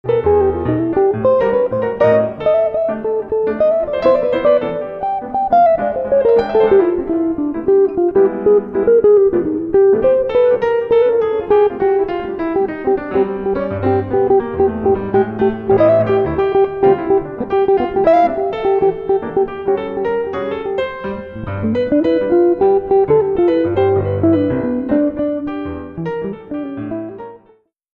Jazz pianist